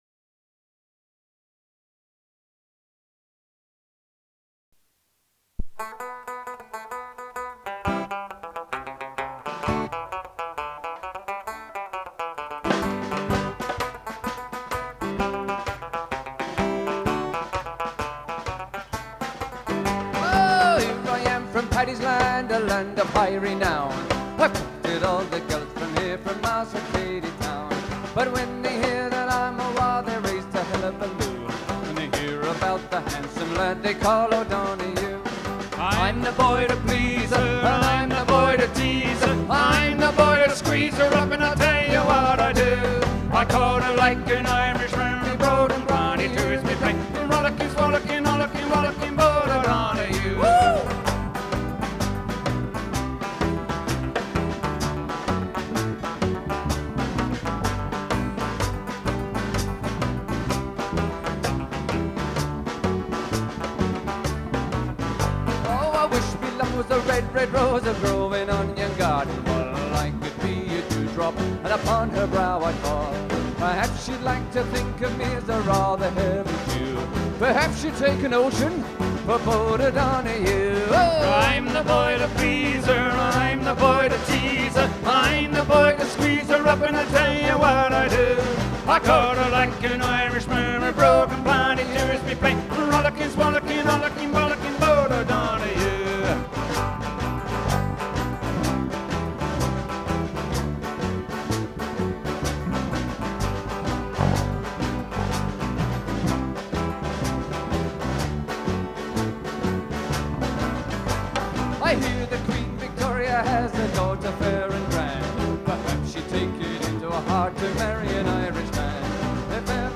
For Irish /Celtic Nights the line up is vocals, guitar, bass, fiddle, mandolin, accordion, mouth organ & our expert percussionist plays both bodhran & conventional drums. We play a mixture of romantic ballads, foot tapping party songs & some driving jigs & reels.